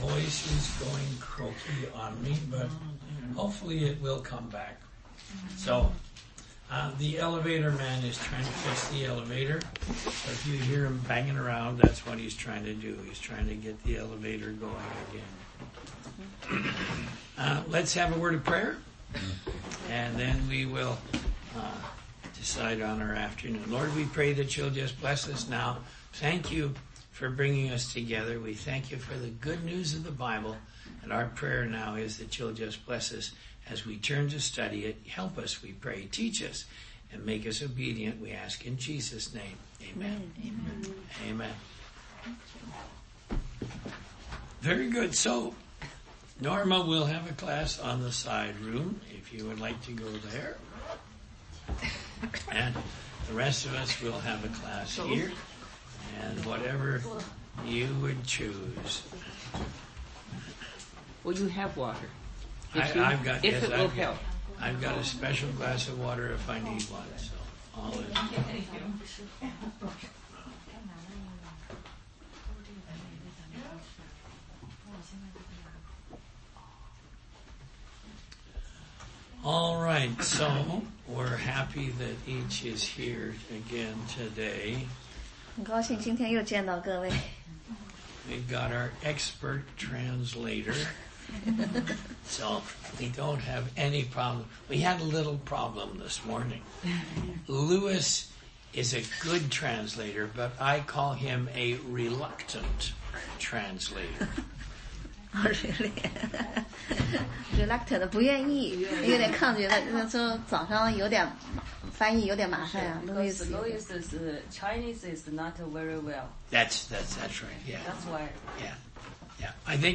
16街讲道录音 - 关于麦基洗德
答疑课程